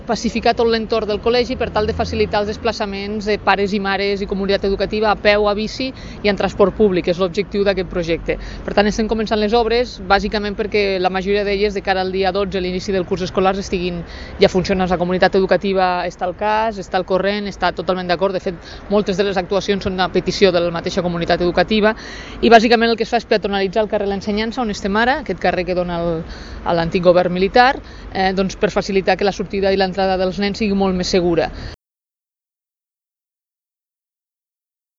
(arxiu de so de la 1a tinent d'alcalde, Marta Camps)